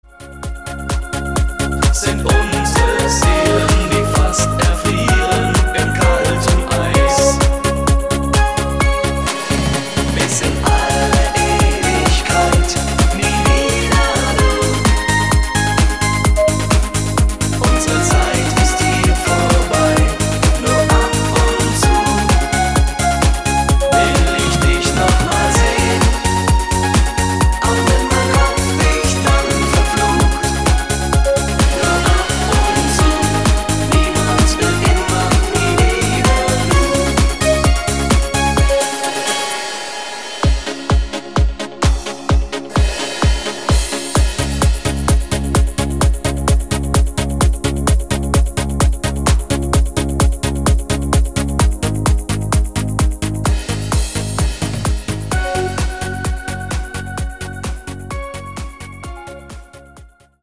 super tanzbarer Song